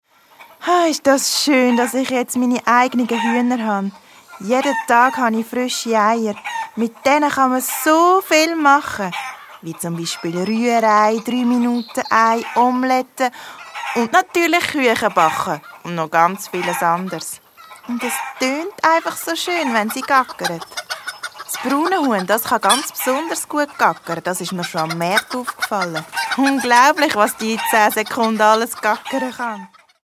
Das letzte Huhn Das Hörspiel nach dem Bilderbuch Das letzte Huhn.